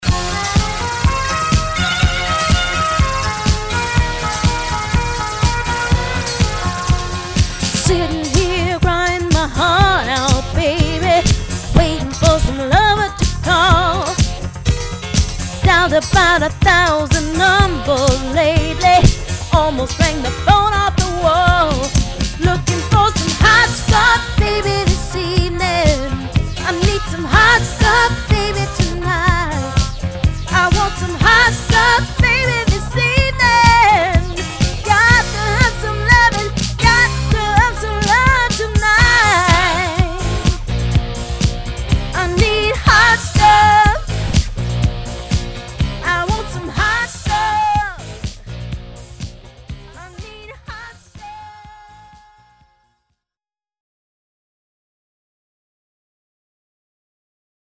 Wedding Singer